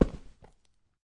Ice_mining6.ogg